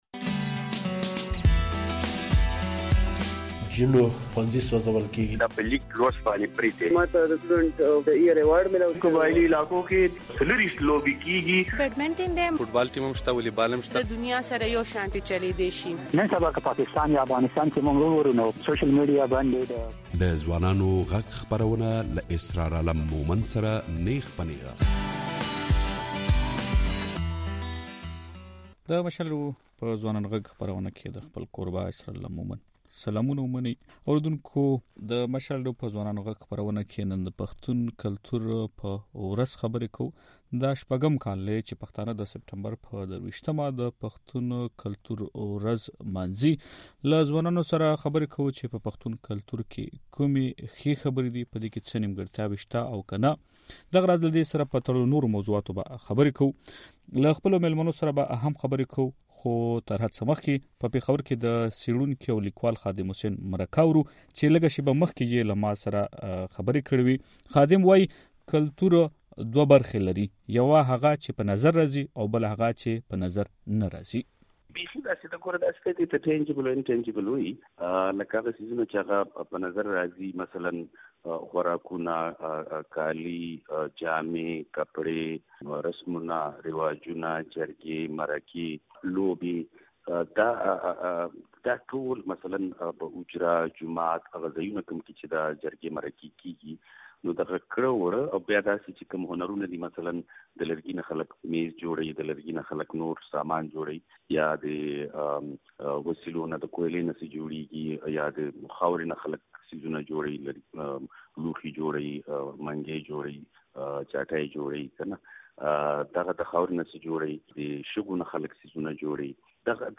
پښتانه ځوانان او پېغلې د خپل کلتور په اړه خبرې کوي